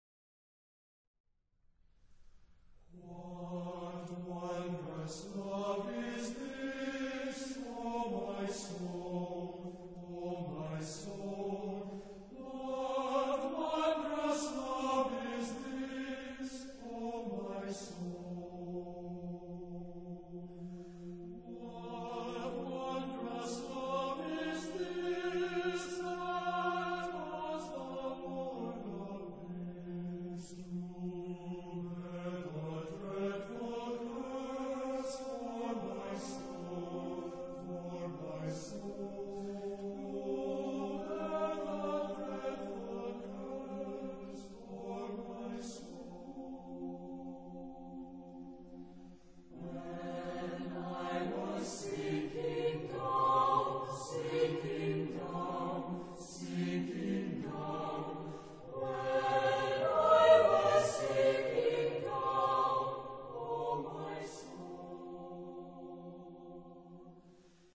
Caractère de la pièce : avec dignité ; ferme
Type de choeur : SATB  (4 voix mixtes )
Tonalité : fa mode de ré
Consultable sous : Populaire Anglophone Sacré Acappella